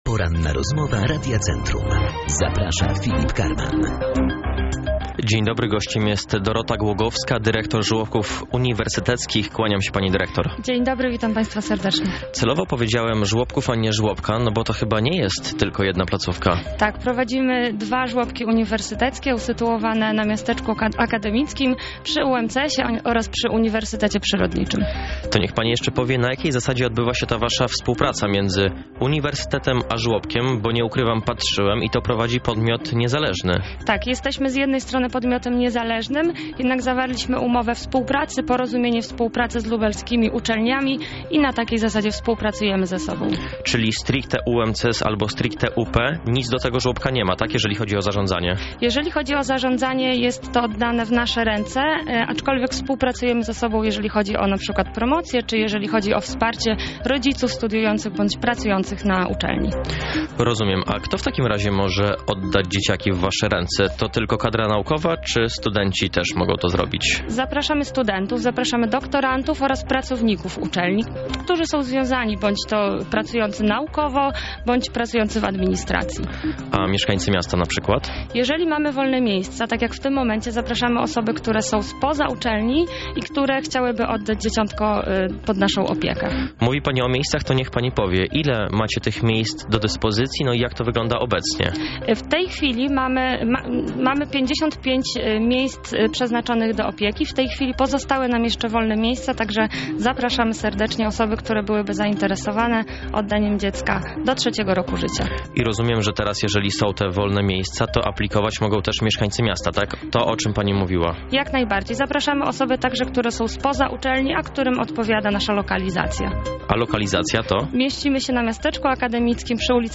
Całą rozmowę